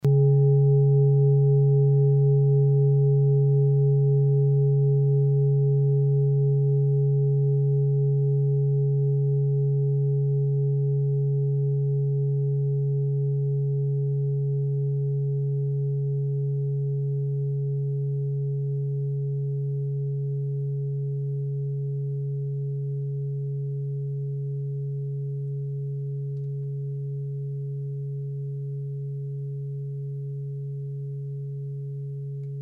Tibet Klangschale Nr.10
Sie ist neu und wurde gezielt nach altem 7-Metalle-Rezept in Handarbeit gezogen und gehämmert.
Hörprobe der Klangschale
(Ermittelt mit dem Filzklöppel)
Der Saturnton liegt bei 147,85 Hz und ist die 37. Oktave der Umlauffrequenz des Saturns um die Sonne. In unserer Tonleiter liegt dieser Ton nahe beim "D".